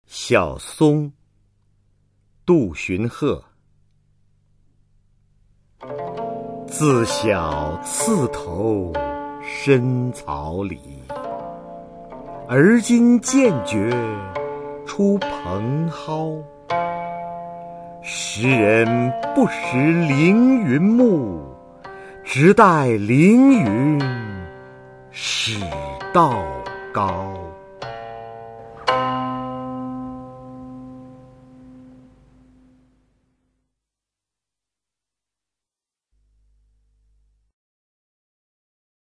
[隋唐诗词诵读]杜荀鹤-小松 古诗文诵读